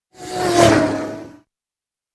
fastlane-car.mp3